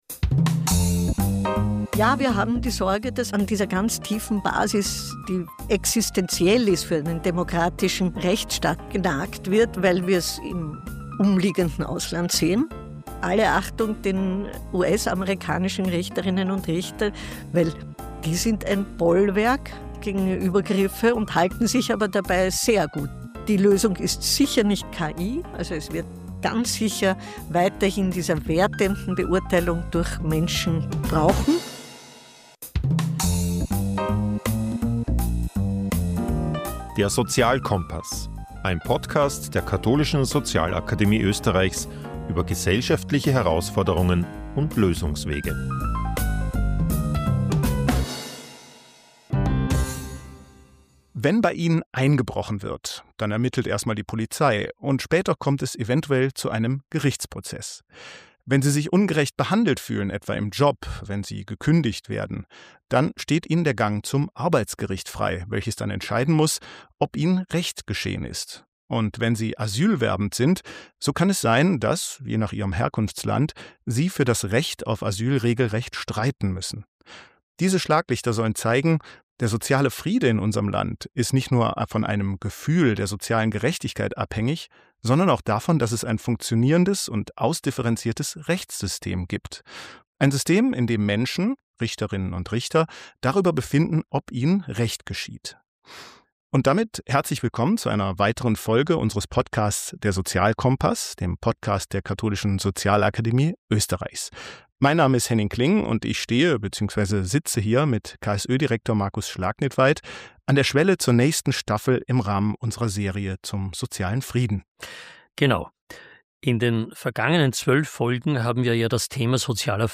Mit ihr sprechen wir über die Rechtsnormen, unter denen sich eine Gesellschaft wohlfühlt und die Bedeutung der Akzeptanz von Recht und Rechtsprechung – auch im Spannungsfeld von Social Media. Außerdem diskutieren wir die Gefahren autoritärer Tendenzen und die Rolle der Europäischen Menschenrechtskonvention sowie die Notwendigkeit, deren Grundlagen zu schützen.